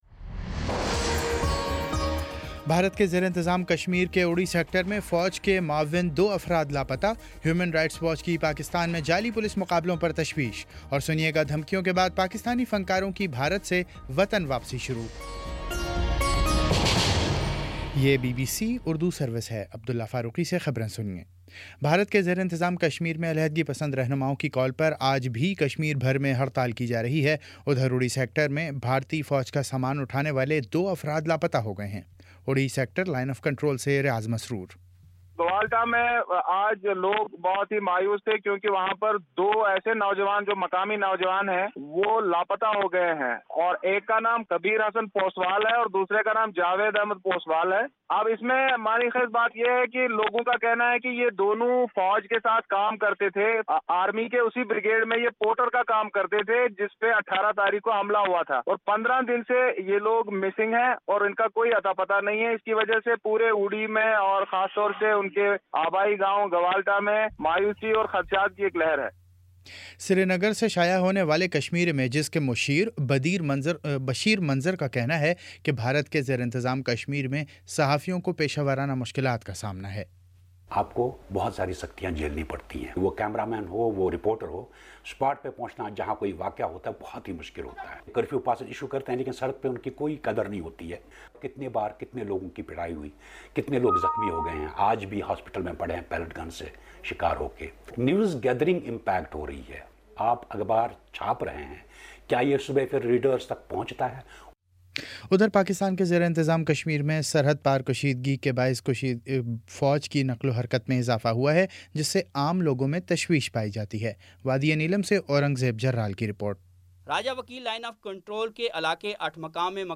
ستمبر26 : شام چھ بجے کا نیوز بُلیٹن